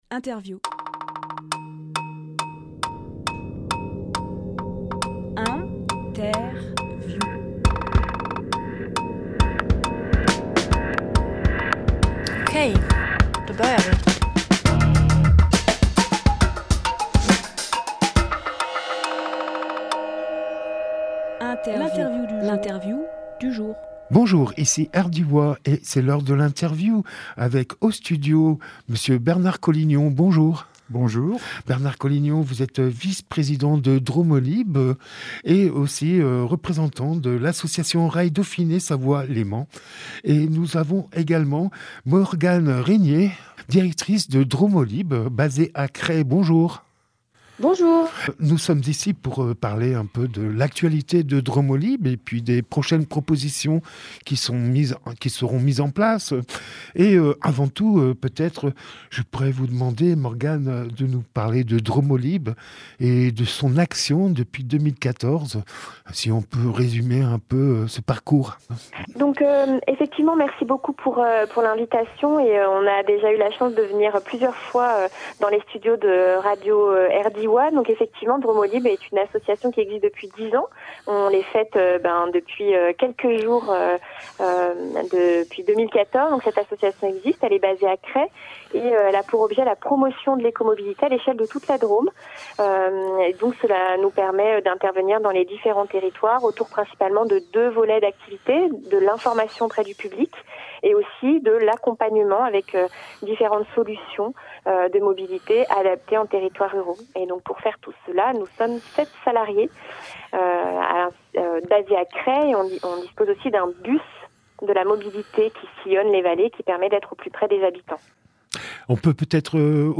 Emission - Interview Dix ans de Dromolib Publié le 22 novembre 2024 Partager sur…
lieu : studio Rdwa